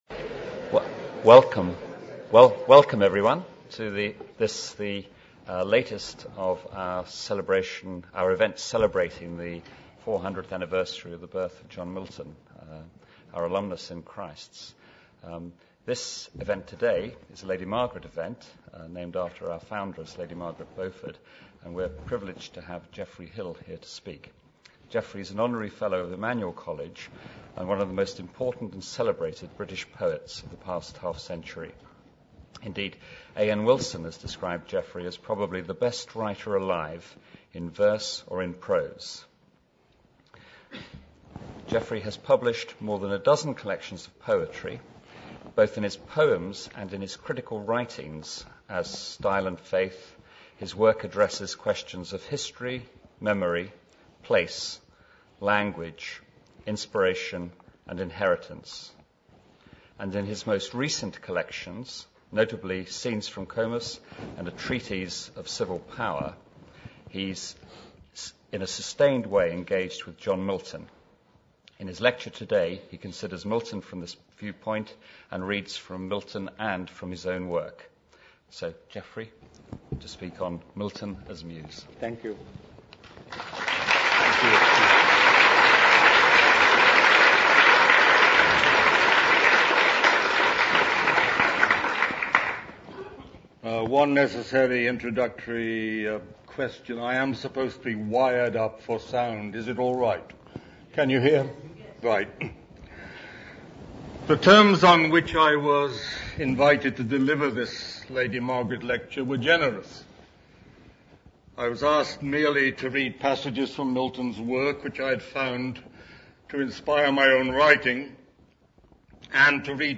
Geoffrey Hill's lecture/reading, given on 29 October 2008, can be listened to here.